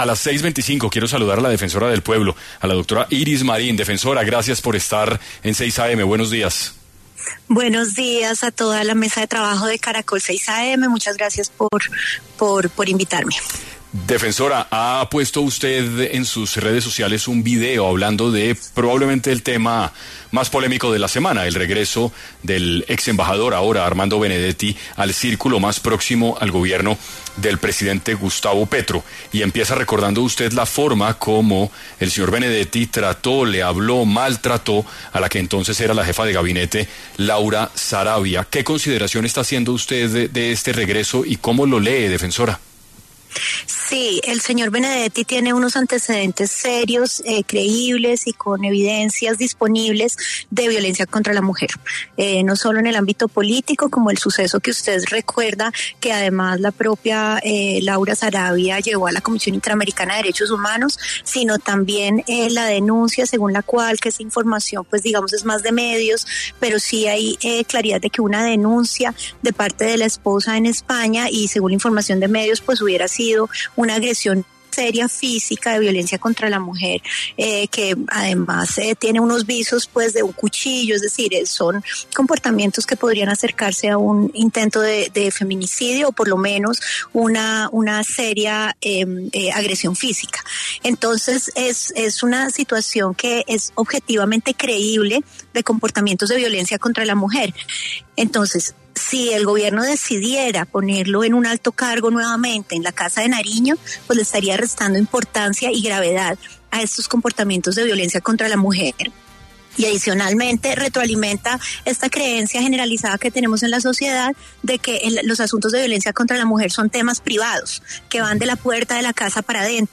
En diálogo con 6AM de Caracol Radio, la defensora Marín reiteró que el machismo es violento y mata, no es un juego y no es un asunto explicable por el abuso de drogas o del alcohol, es inadmisible y por eso alza su voz para pedirle al presidente Gustavo Petro no ratificar a Armando Benedetti en este cargo: